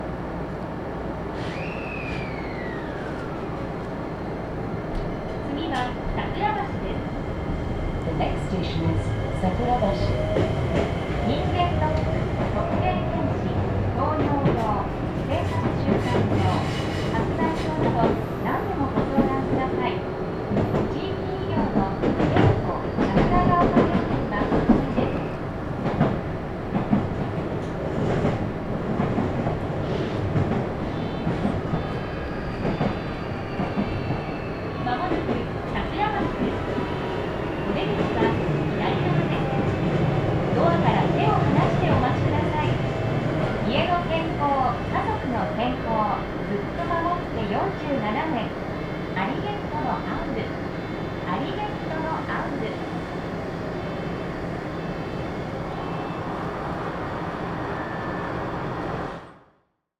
車内は朝のラッシュでなかなかの混雑。
扉が閉まり、電車が動き出す。
少し走っては停まり、また走る。その繰り返し。
0014irieoka-sakurabashi.mp3